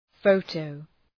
Προφορά
{‘fəʋtəʋ}